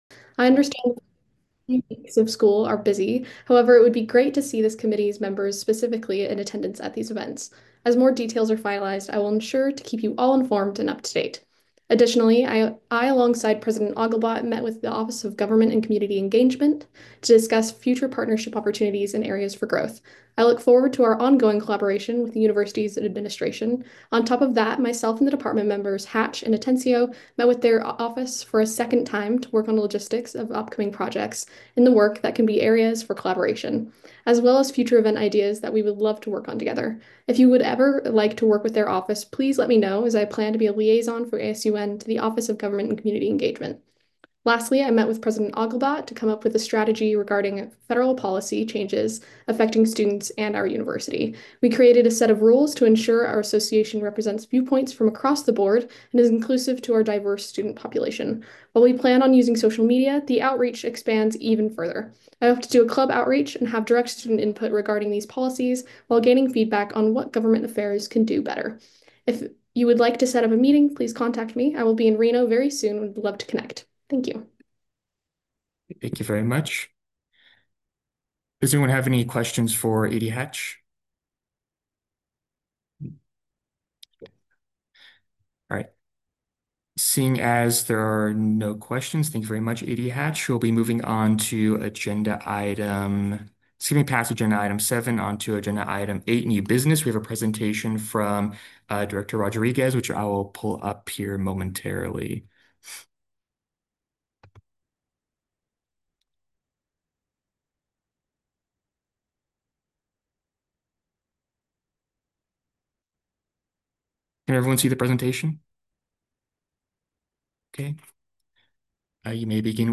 Audio Minutes